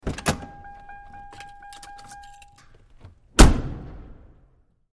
Lincoln Towncar Door Slams, In Garage And Outdoors